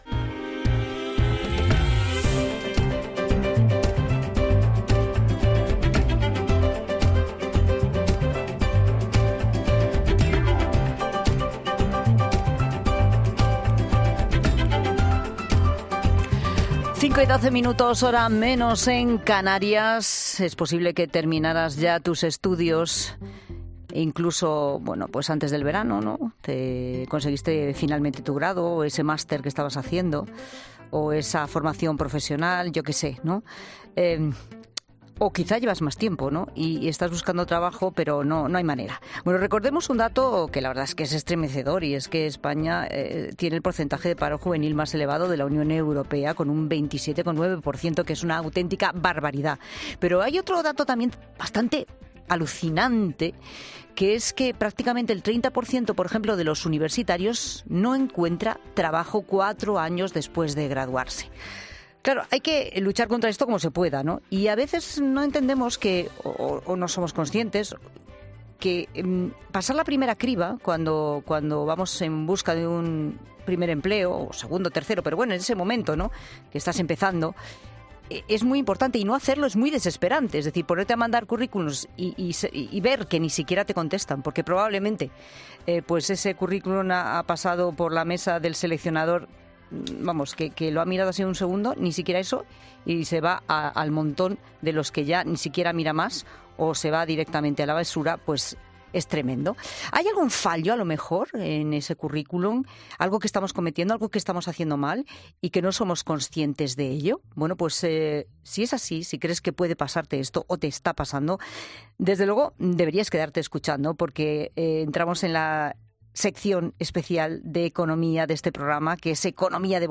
En La Tarde, el economista y escritor, Fernando Trías de Bes, ha dado algunos consejos para hacer un buen Currículum y que de esta forma el empleador...